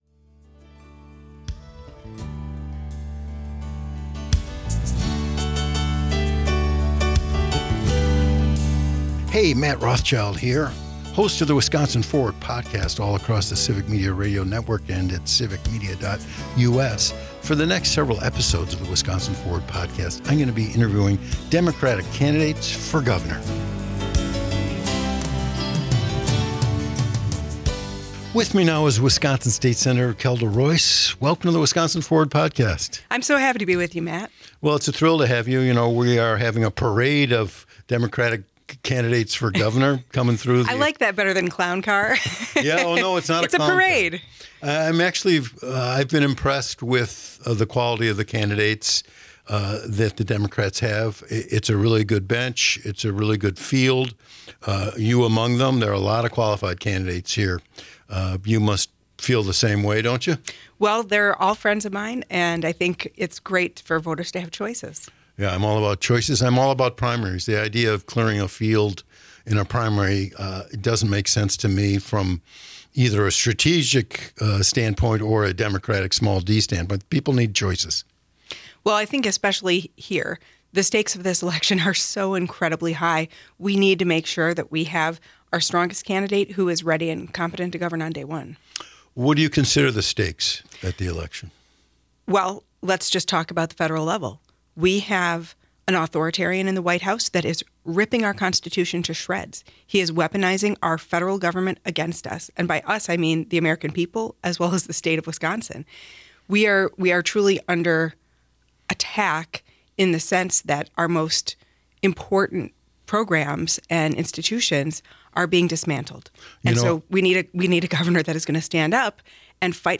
An Interview with Kelda Roys - Civic Media